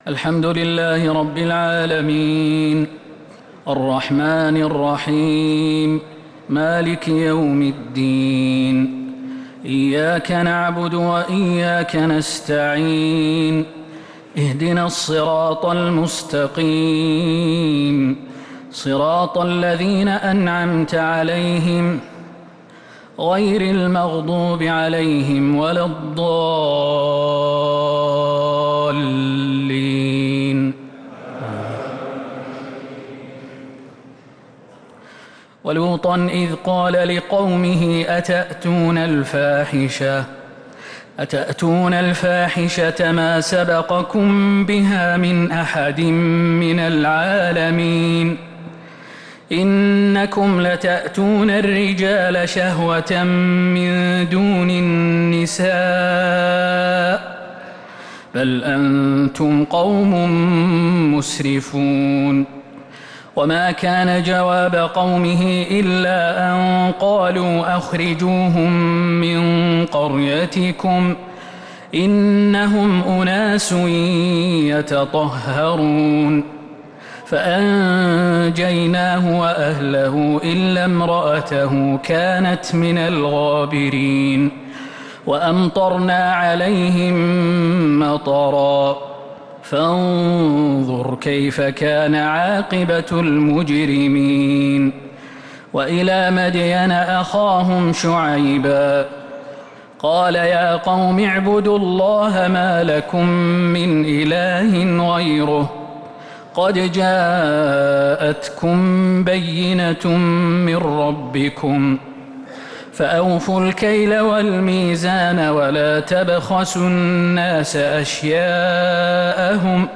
تراويح ليلة 11 رمضان 1447هـ من سورة الأعراف (80-141) | Taraweeh 11th niqht Ramadan Surat Al-A’raf 1447H > تراويح الحرم النبوي عام 1447 🕌 > التراويح - تلاوات الحرمين